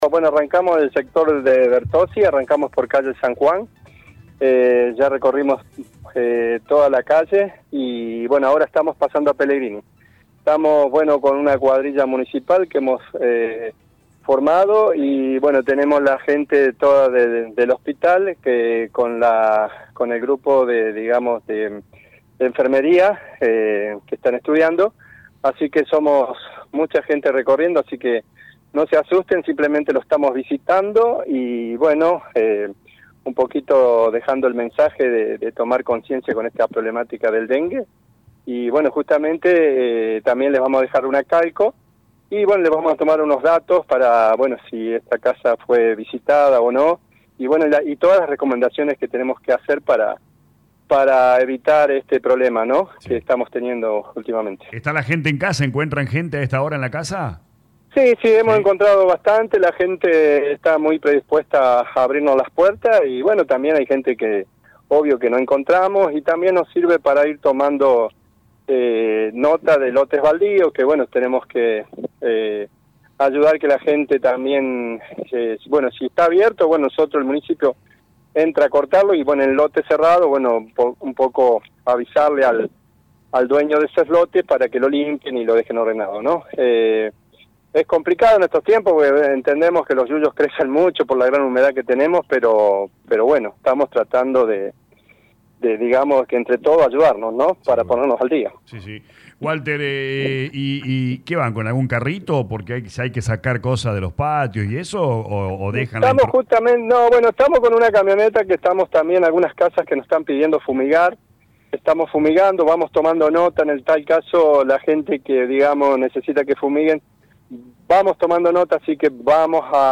El secretario de servicios públicos y ambiente, Sr Walter Olivera dialogó con LA RADIO 102.9 en el inicio del recorrido y remarcó que la actividad consiste en controlar en los hogares todo tipo de objetos que representen un potencial criadero, pueden estar adentro de las casas, patios, jardines o peridomicilios.